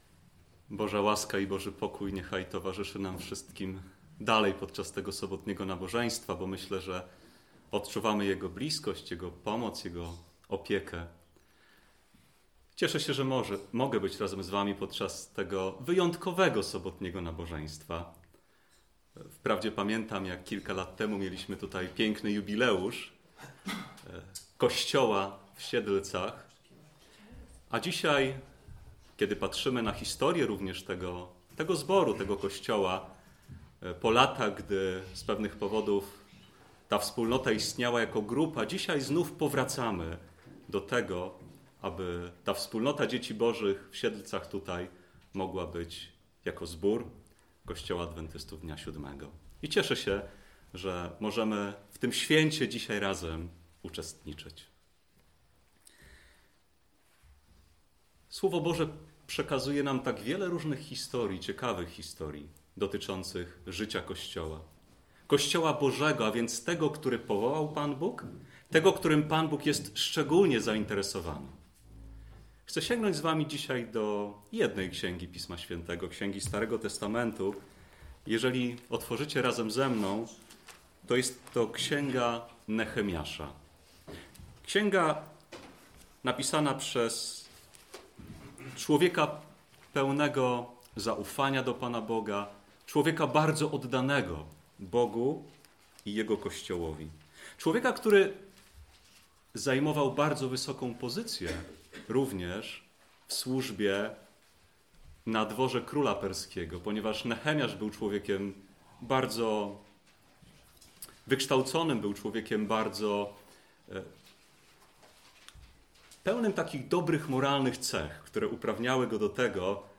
Uroczystość powołania zboru w Siedlcach – Diecezja Wschodnia
Dnia 8. grudnia 2018 r. w Siedlcach odbyło się uroczyste nabożeństwo podczas, którego na nowo powołano do istnienia zbór Kościoła Adwentystów Dnia Siódmego.